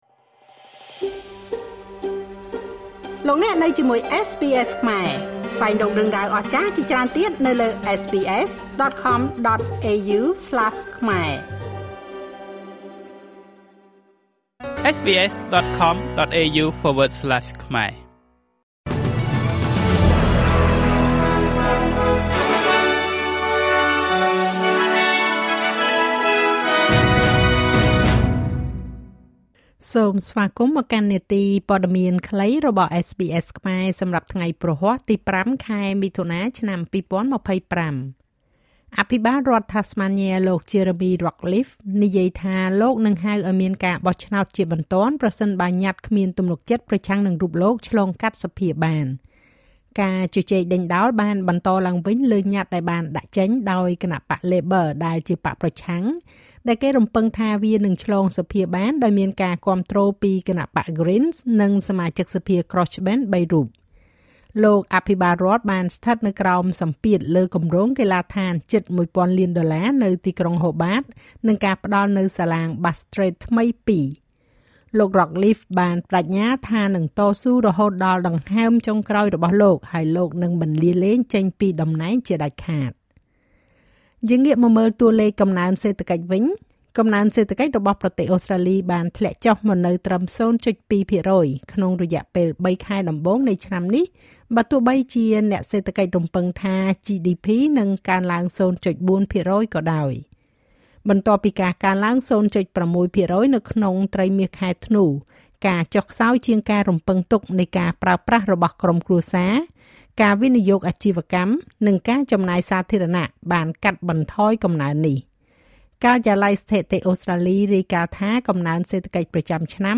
នាទីព័ត៌មានខ្លីរបស់SBSខ្មែរ សម្រាប់ថ្ងៃព្រហស្បតិ៍ ទី៥ ខែមិថុនា ឆ្នាំ២០២៥